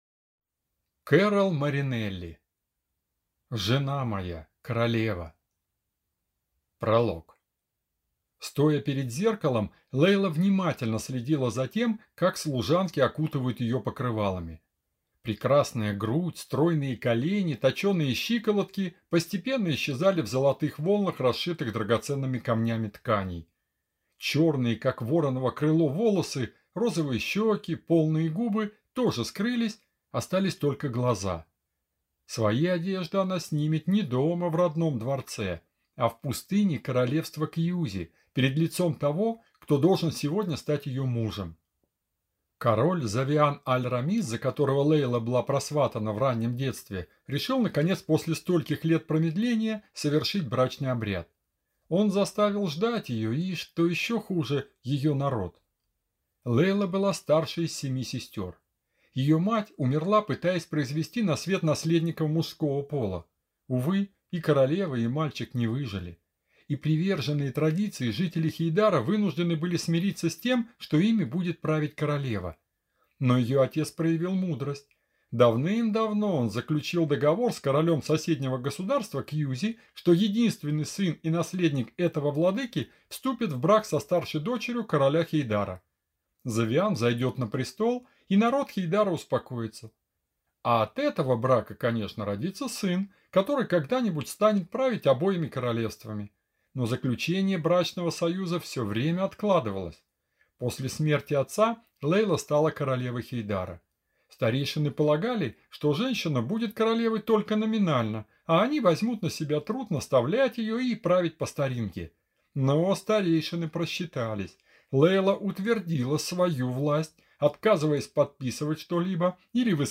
Аудиокнига Жена моя, королева | Библиотека аудиокниг